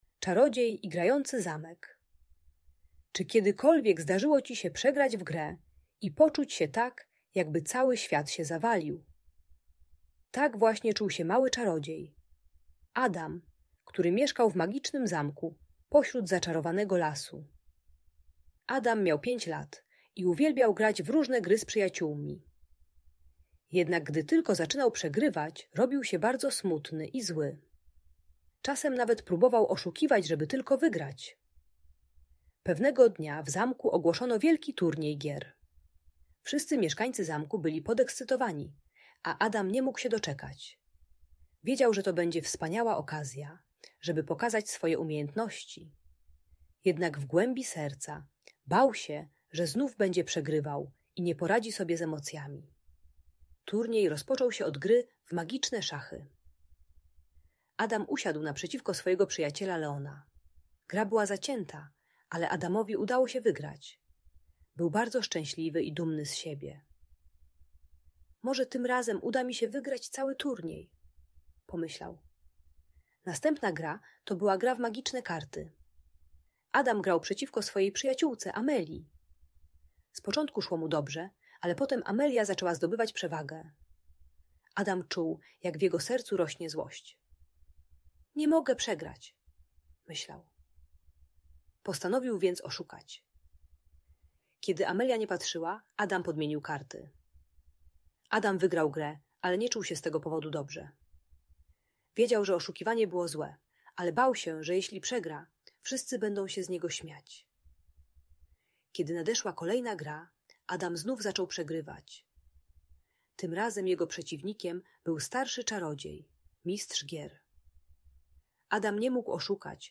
Czarodziej i Grający Zamek - Audiobajka